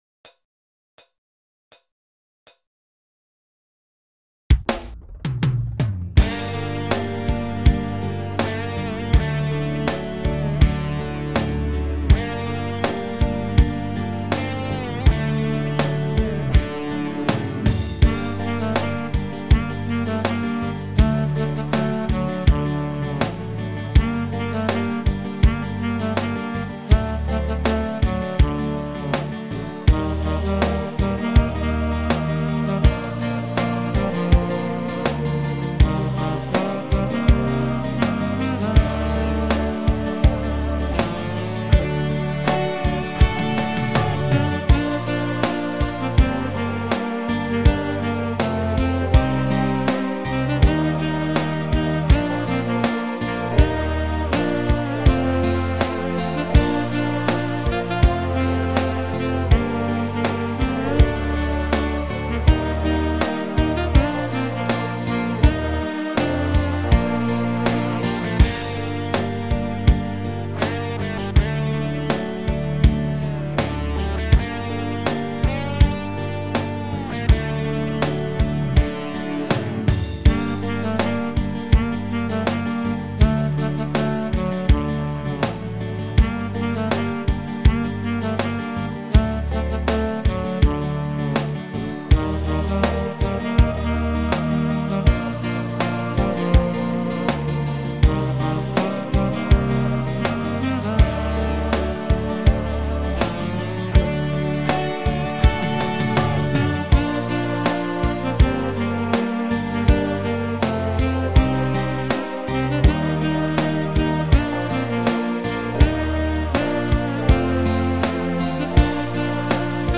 PRO MIDI Karaoke INSTRUMENTAL VERSION
Alpenrock